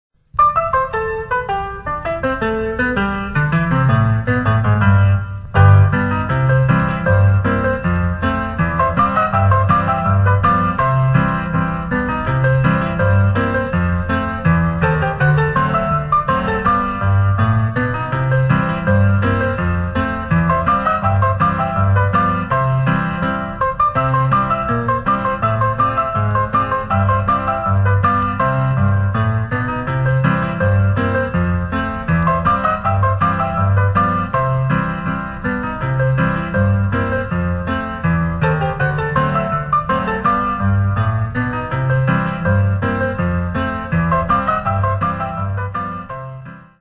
Mais oui, tu connais la musique de Scott Joplin, le ragtime ! Un montage photo bidouillé à la hâte et quelques notes de piano à rouleau et ta mémoire fera le reste :
sampler-ragtime-piano-scott-joplin-the-entertainer-1902.mp3